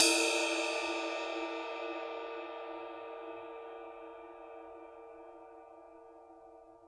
susCymb1-hitstick_f_rr1.wav